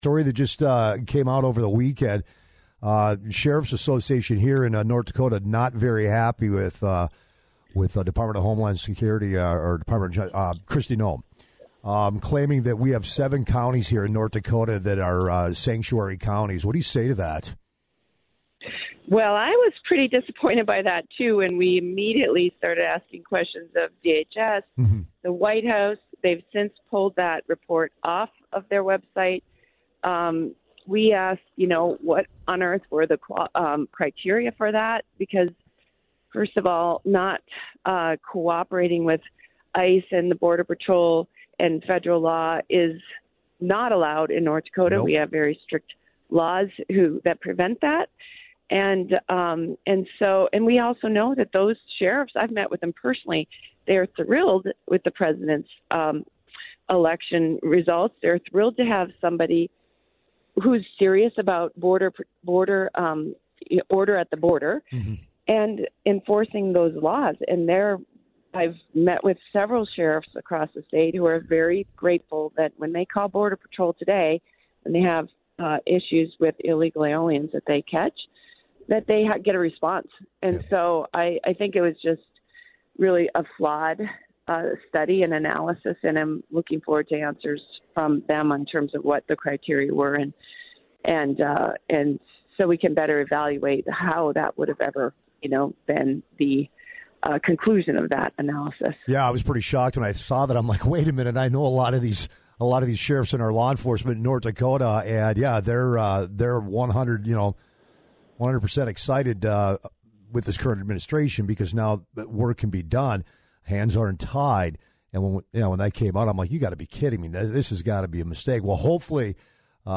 fedorchak-immigration-interview.mp3